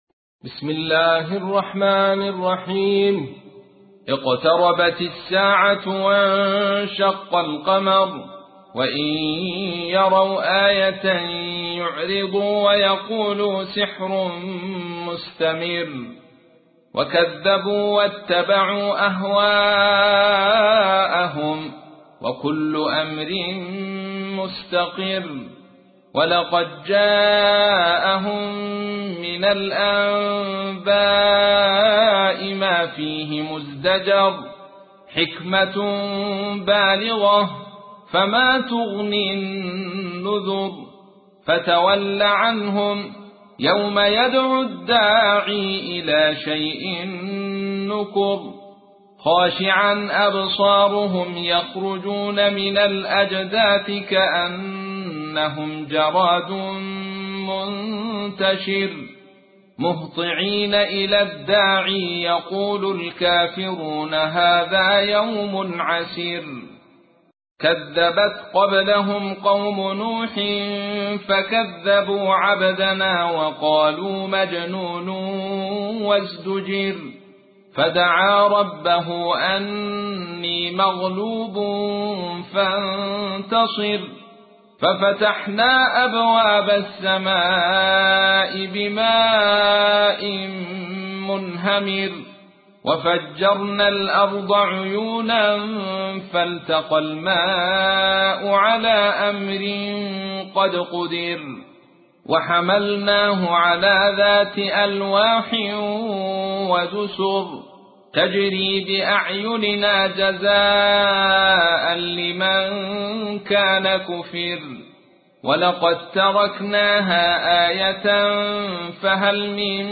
54. سورة القمر / القارئ